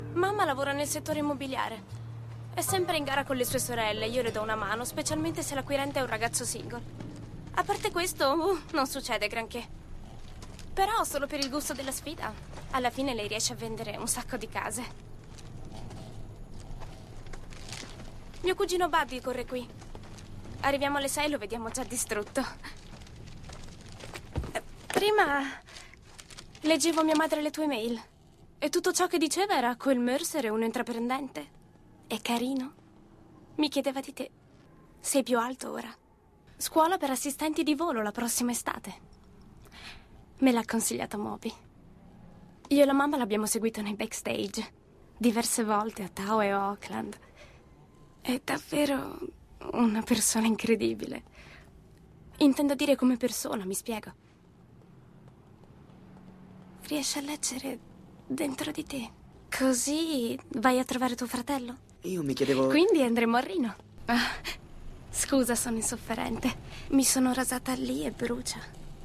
nel film "American Sunshine", in cui doppia Jena Malone.